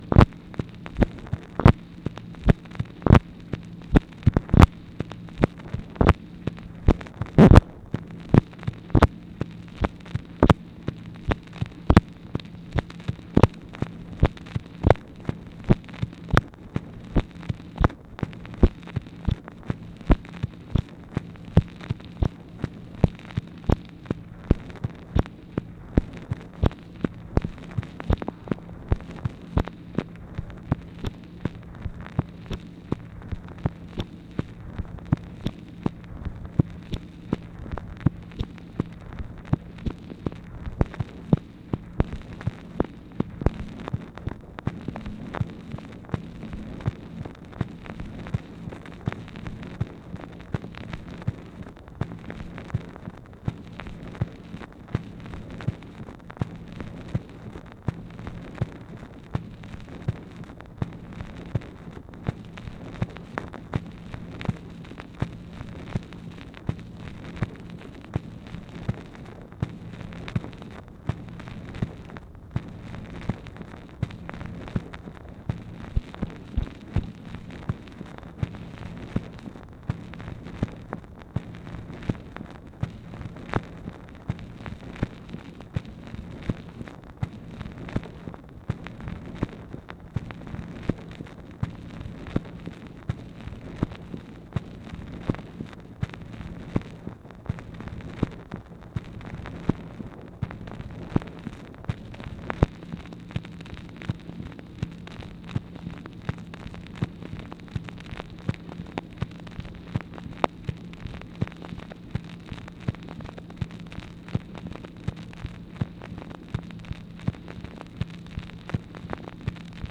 MACHINE NOISE, April 10, 1964